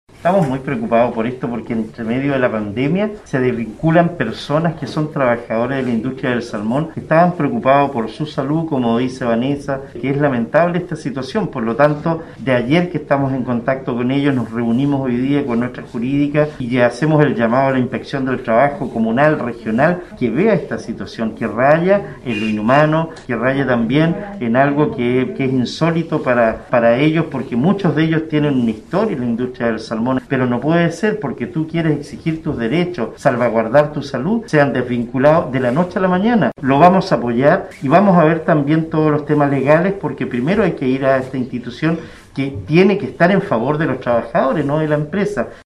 Por su parte, el alcalde Cristian Ojeda, expresó que se respaldará jurídicamente a los trabajadores que fueron desvinculados por el solo hecho de querer defender su salud y la de sus familias, a causa de los casos de coronavirus que existen en las plantas de proceso.